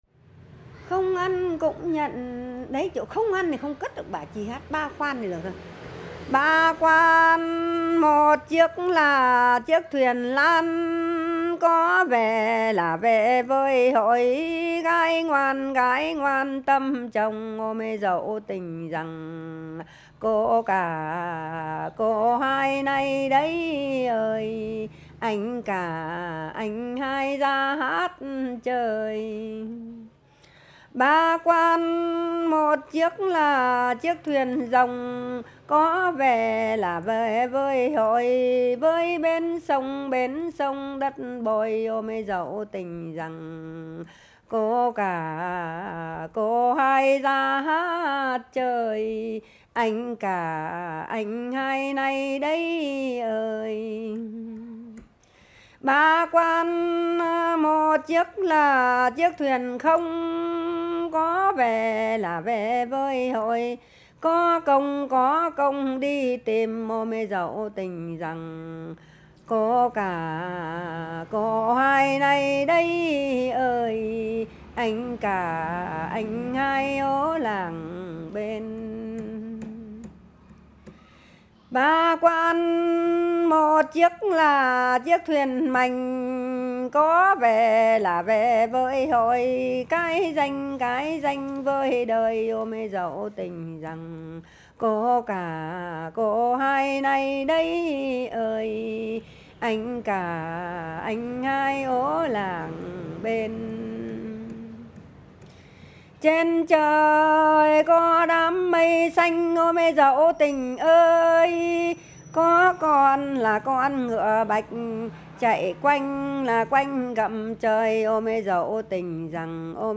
I recorded the songs in 2004, in the city of Bac Ninh, just outside Hanoi.